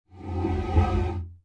BarrelMove.ogg